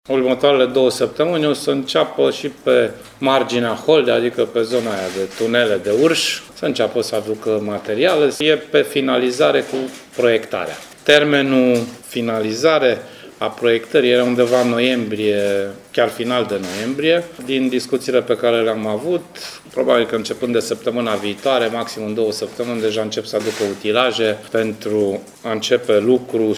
Ordinul de începere a fost semnat, deja, iar organizarea de șantier începe săptămâna viitoare, a anunțat ministrul Transporturilor, Sorin Grindeanu, prezent astăzi la Timișoara.
În perioada următoare, aceeași firmă care lucrează pe centura de Sud demarează și lucrările pe autostrada A 1 între, între Margina și Holdea, mai spune ministrul Sorin Grindeanu: